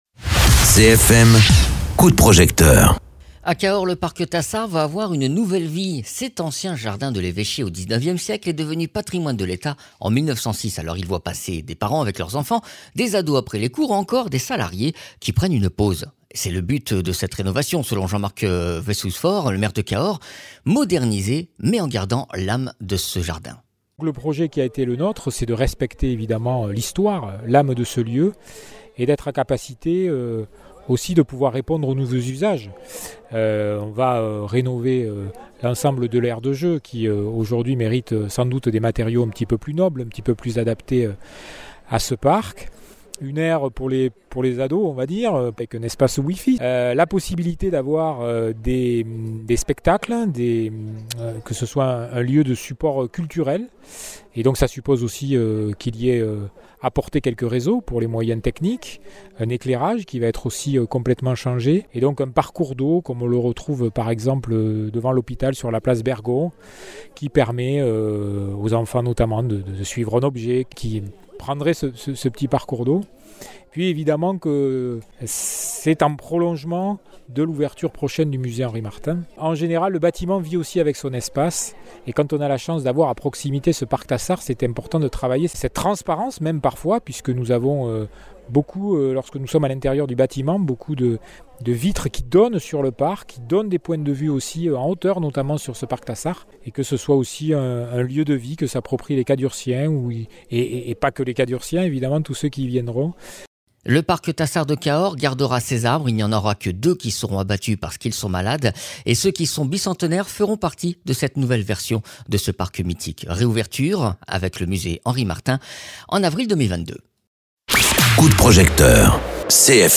Interviews
Invité(s) : Jean Marc Vayssouze Faure, Maire de Cahors